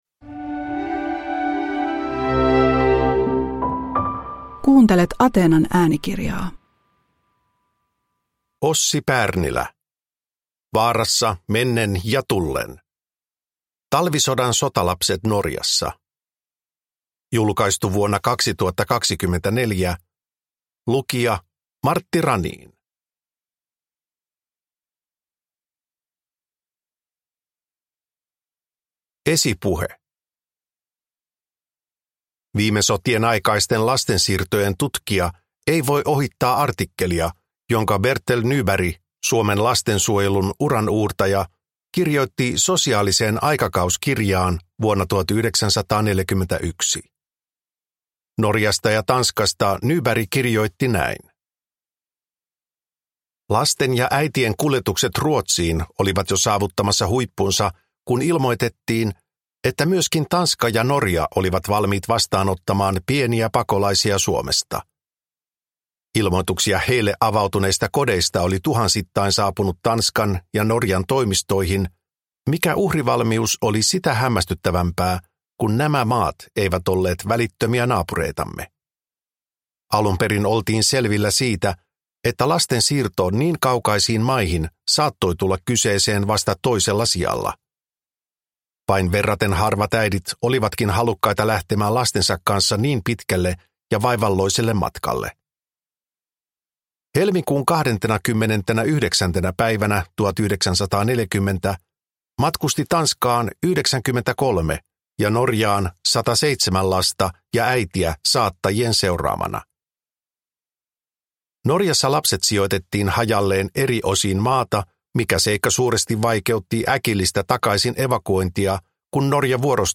Vaarassa mennen ja tullen – Ljudbok